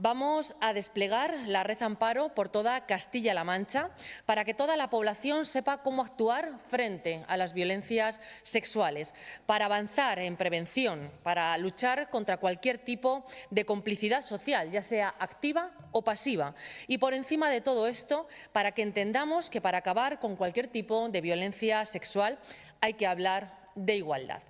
Durante la presentación de la Red, en el Palacio de Fuensalida, la titular regional de Igualdad ha desgranado los cinco grandes hitos que la Consejería se marca con esta estrategia, que englobará medidas puestas en marcha desde todas las áreas de gobierno durante los años 2026 y 2027.